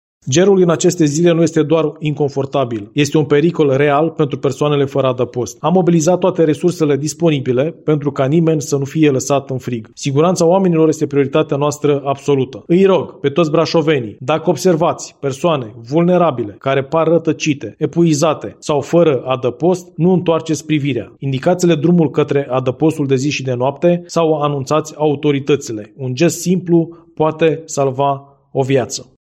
Viceprimarul Municipiului Brașov, Lucian Pătrașcu: